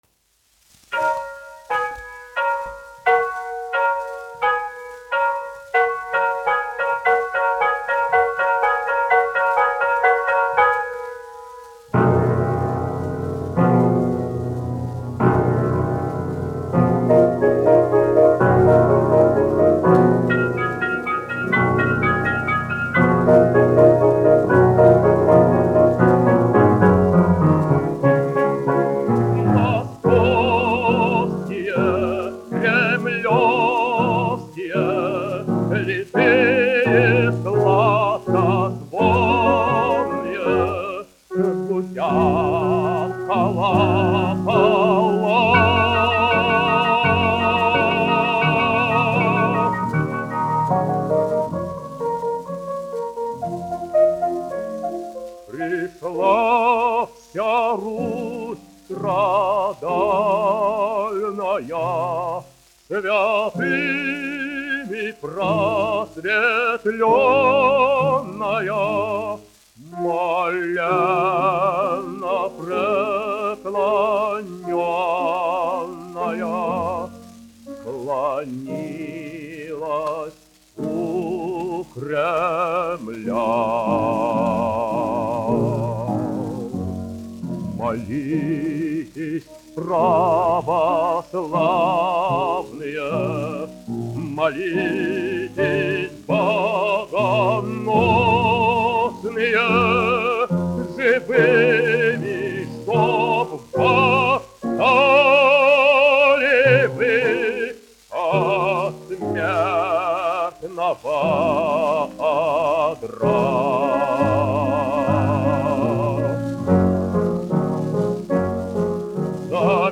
1 skpl. : analogs, 78 apgr/min, mono ; 25 cm
Romances (mūzika)
Skaņuplate